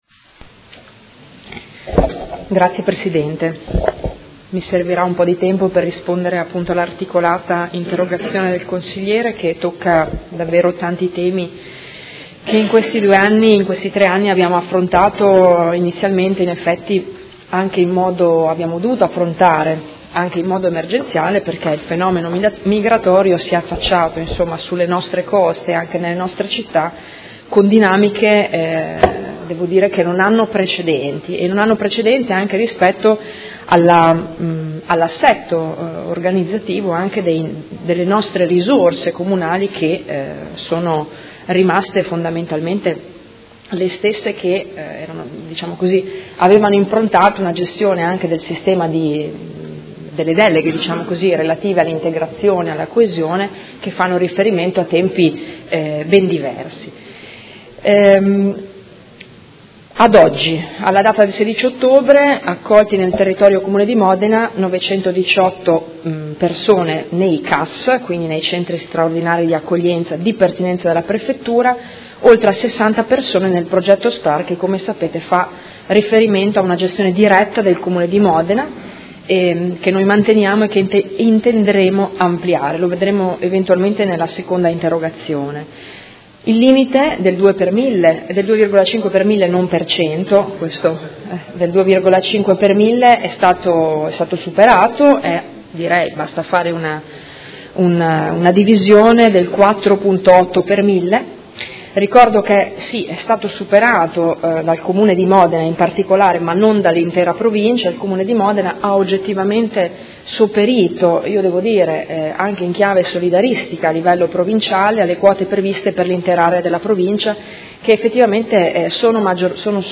Giuliana Urbelli — Sito Audio Consiglio Comunale
Seduta del 26/10/2017. Risponde a interrogazione del Consigliere Pellacani (FI) avente per oggetto: Gestione dei rifugiati e richiedenti asilo nel Comune di Modena